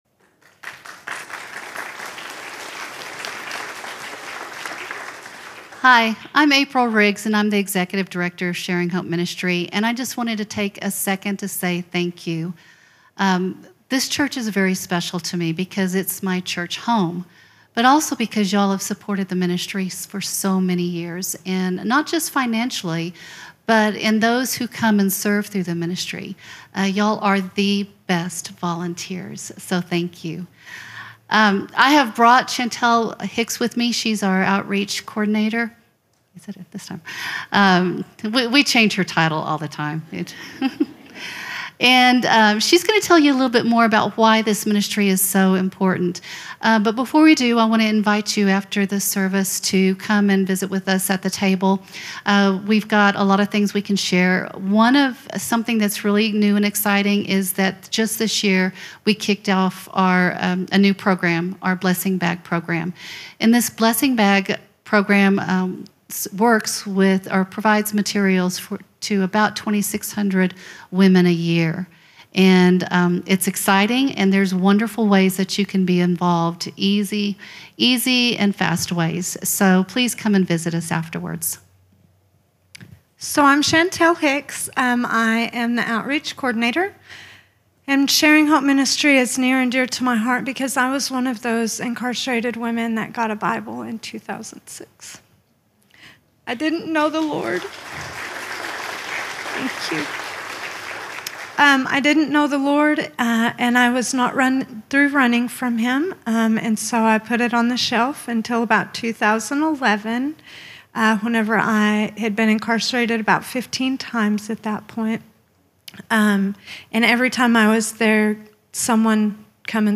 sermon audio 0817.mp3